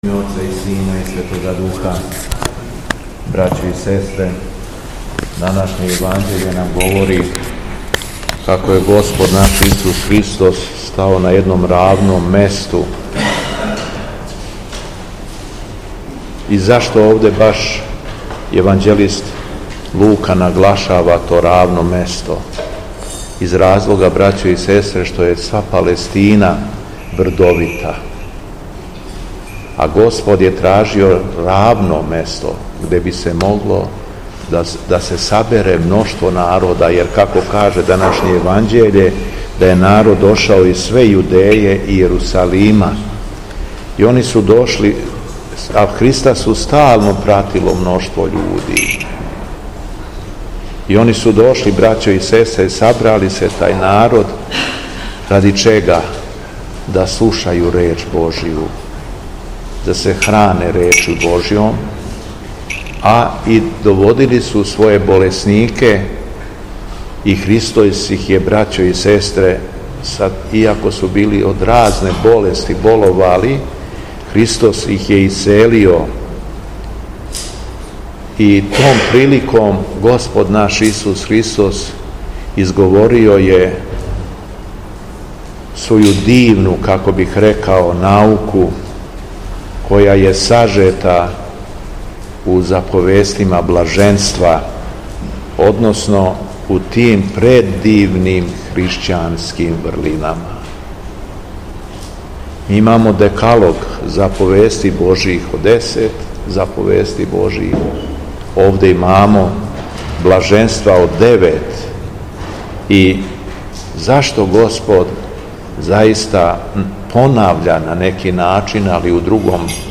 ЛИТУРГИЈСКО САБРАЊЕ У СТАРОЈ ЦРКВИ У КРАГУЈЕВЦУ - Епархија Шумадијска
Беседа Његовог Високопреосвештенства Митрополита шумадијског г. Јована
Беседом се верном народу обратио Високопреосвећени Митрополит Јован рекавши да: